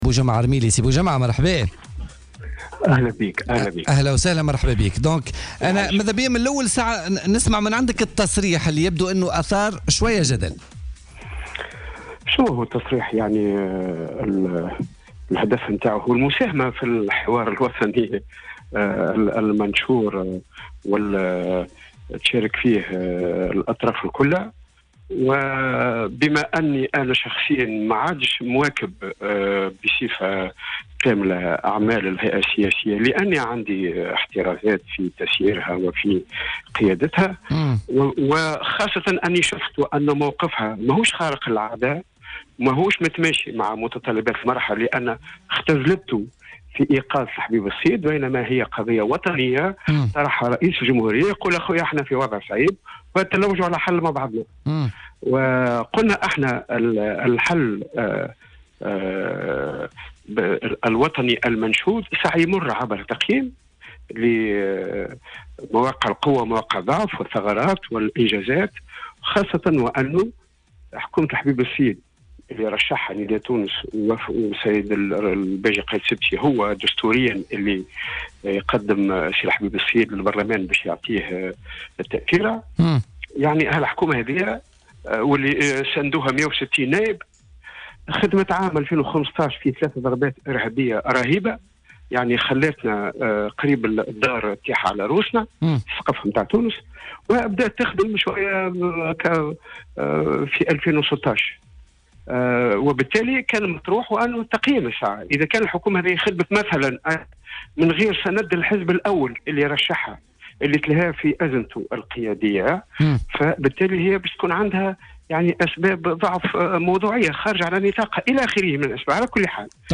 وأوضح الرميلي في تصريح للجوهرة أف أم في برنامج الحدث لليوم الخميس 30 جوان 2016 أنه كان من المفروض تقييم عمل حكومة الحبيب الصيد قبل طرح مبادرة حكومة الوحدة الوطنية وتقديم برامج بديلة وجديدة للحكومة المزمع تكوينها.